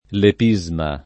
lepisma [ lep &@ ma ]